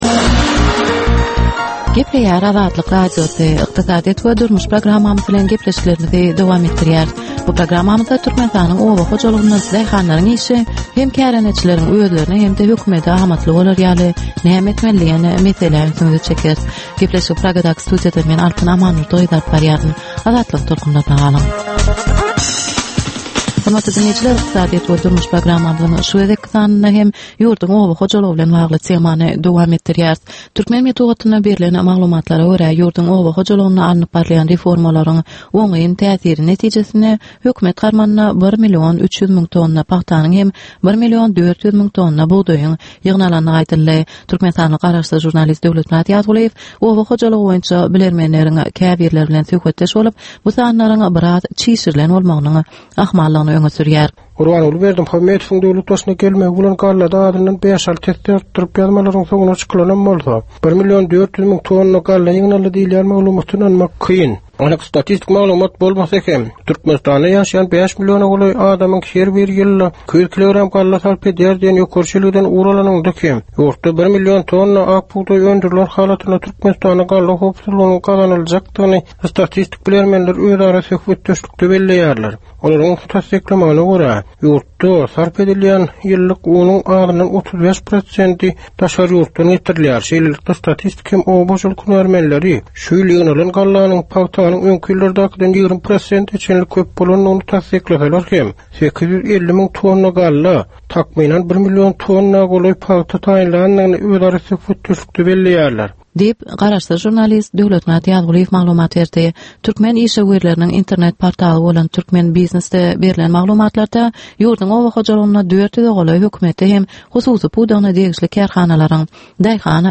Türkmenistanyň ykdysadyýeti bilen baglanyşykly möhüm meselelere bagyşlanylyp taýýarlanylýan ýörite gepleşik. Bu gepleşikde Türkmenistanyň ykdysadyýeti bilen baglanyşykly, şeýle hem daşary ýurtlaryň tejribeleri bilen baglanyşykly derwaýys meseleler boýnça dürli maglumatlar, synlar, adaty dinleýjileriň, synçylaryň we bilermenleriň pikirleri, teklipleri berilýär.